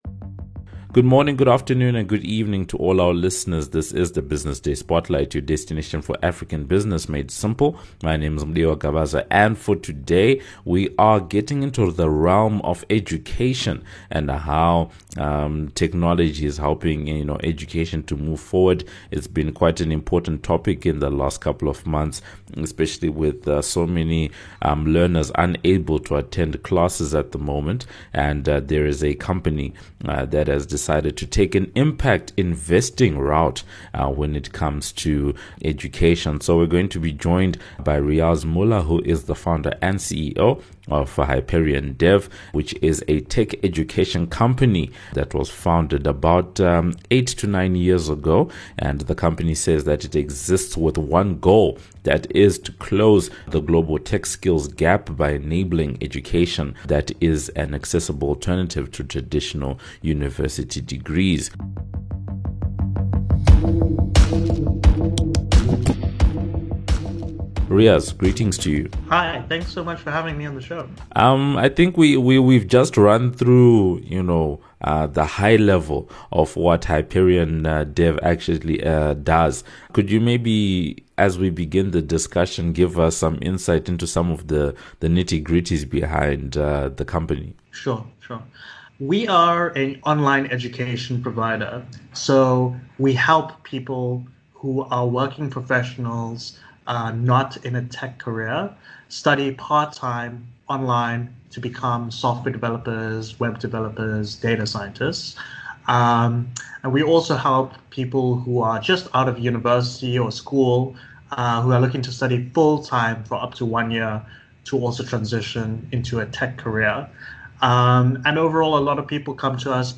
The conversation focuses on HyperionDev’s business model, the company’s scholarship program, career prospects of people with data driven or programming qualifications, the evolution of online learning in SA, the effect of Covid-19 on the sector, as well as detailing the barriers still holding many learners in SA from being able to take advantage of online learning.